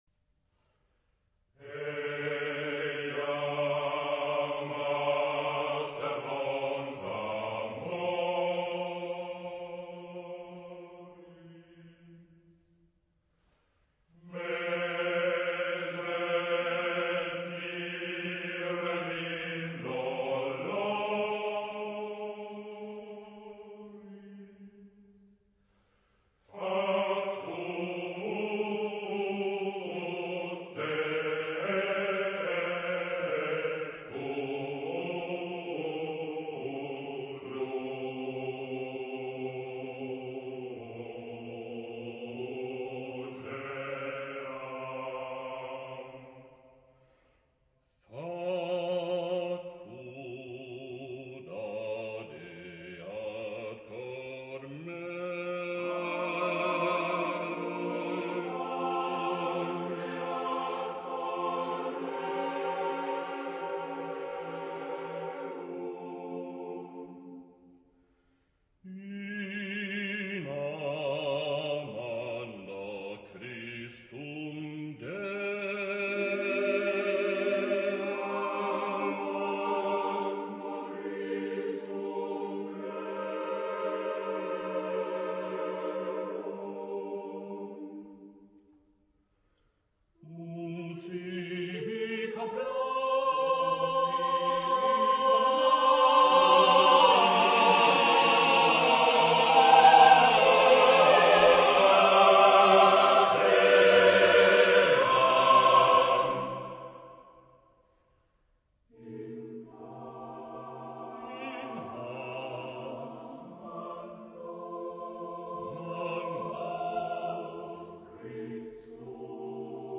Epoque: 19th century
Genre-Style-Form: Sacred ; Romantic
Type of Choir: SATB mixed